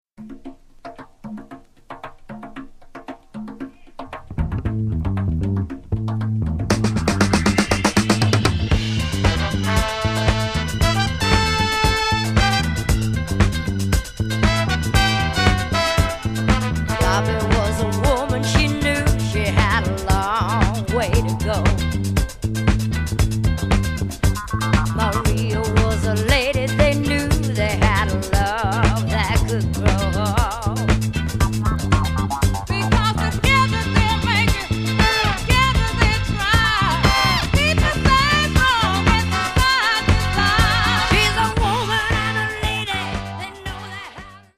FREE SOUL/RARE GROOVE
ギター